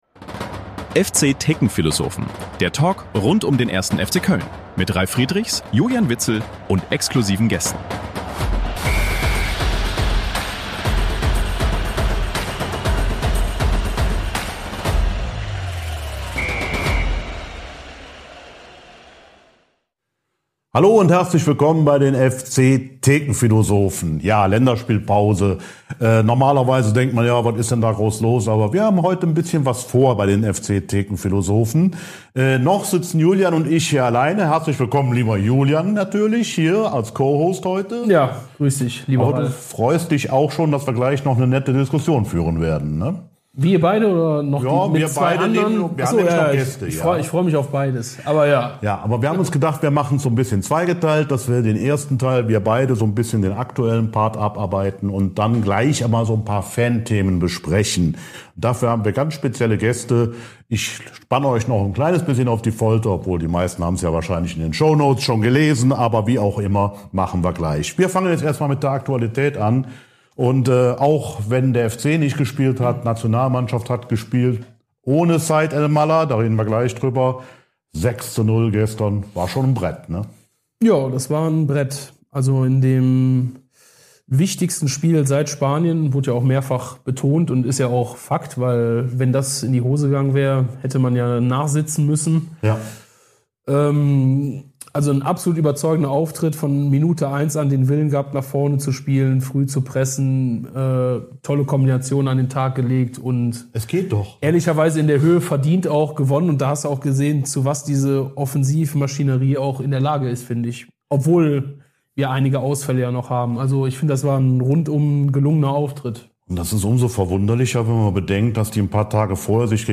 In der neuen Ausgabe der „FC-Thekenphilosophen“ erwartet euch ein besonderer, zweiteiliger Talk!